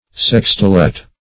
Search Result for " sextolet" : The Collaborative International Dictionary of English v.0.48: Sextolet \Sex"to*let\, n. (Mus.) A double triplet; a group of six equal notes played in the time of four.